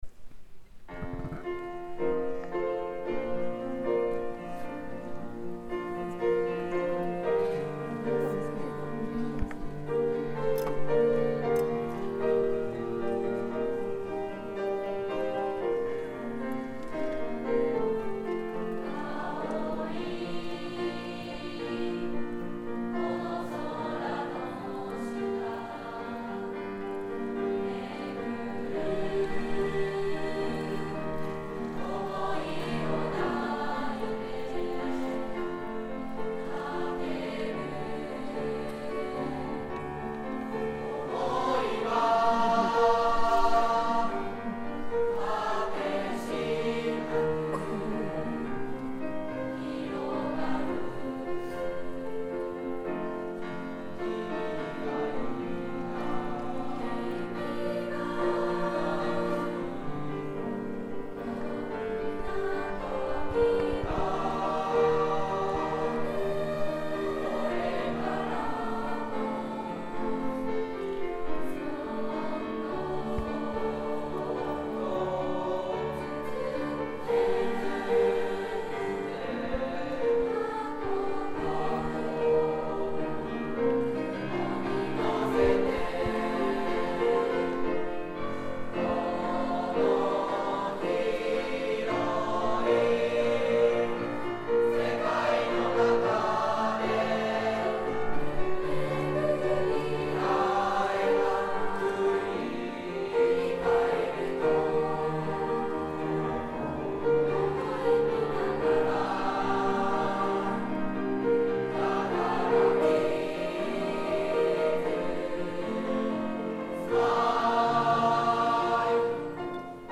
２Ｇ With You Smile.mp3 ←クリックすると合唱が聴けます